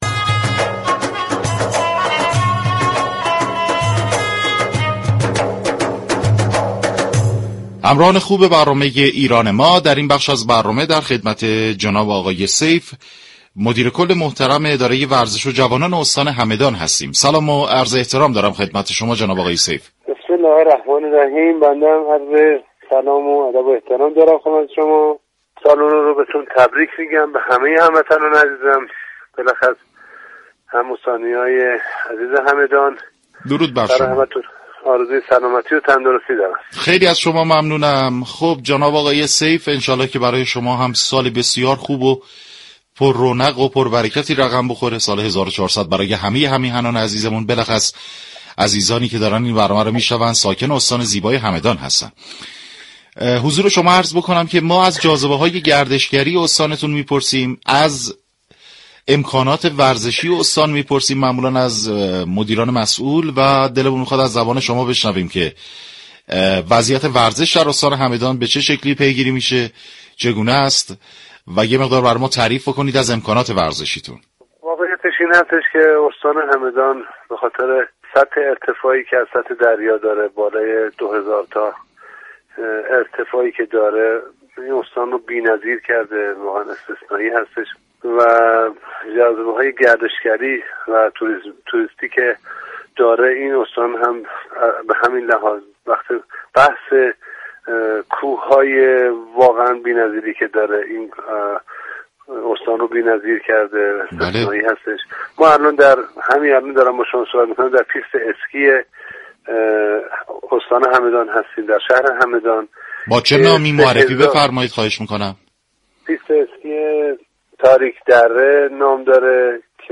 به گزارش رادیو ورزش؛ سیف، مدیركل ورزش و جوانان استان همدان، در ارتباط با وضعیت ورزش و جاذبه های گردشگری این استان توضیحاتی را ارائه كرد كه شما مخاطب محترم می توانید از طریق فایل صوتی پیوست شنونده این گفتگو باشید. مجله رادیویی «ایران ما» آداب و رسوم نوروزی در استان‌ ها و پیشكسوتان استان‌ ها را معرفی و همچنین چشم انداز فعالیت های ورزشی را بررسی می‌ كند.